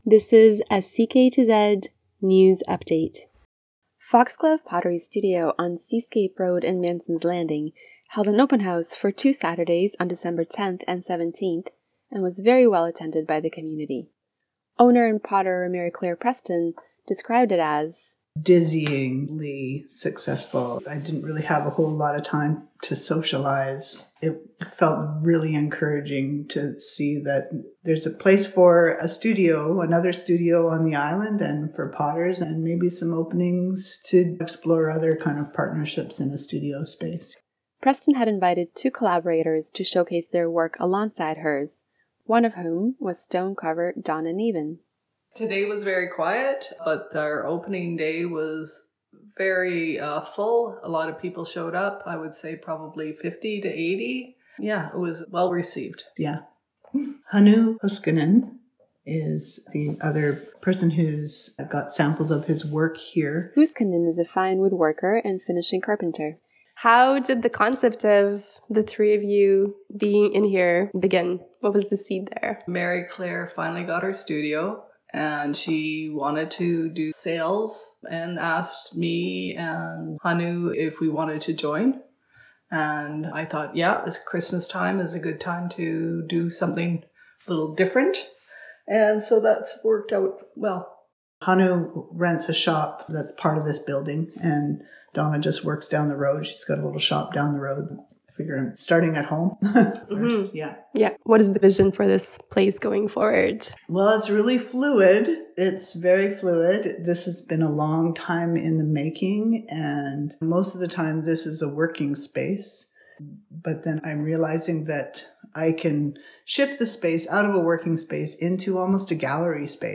CKTZ News interview